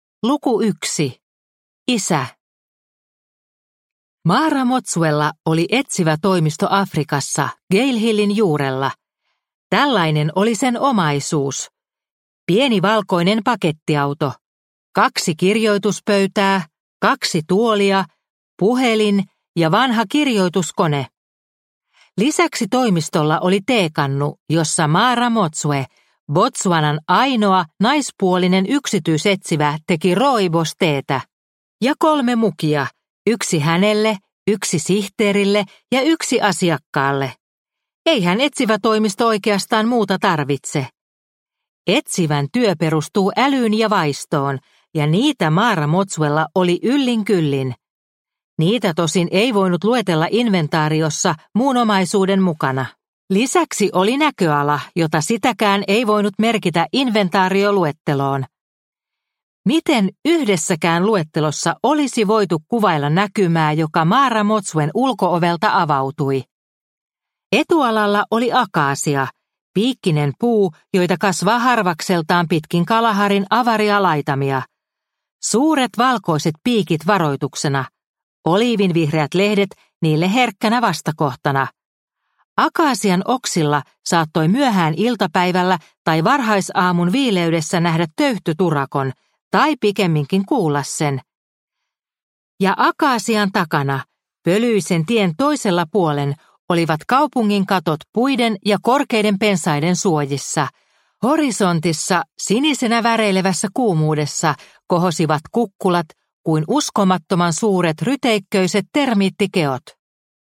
Naisten etsivätoimisto nro 1 – Ljudbok – Laddas ner